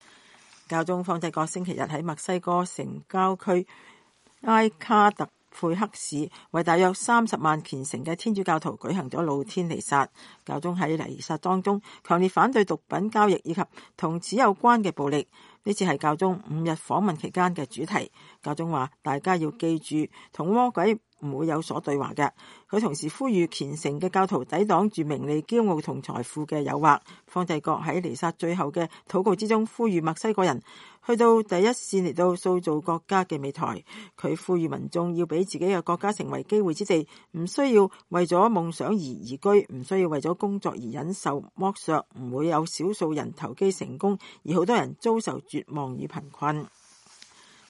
教宗方濟各星期天在墨西哥城郊區埃卡特佩克市為大約30萬虔誠的天主教徒舉行露天彌撒。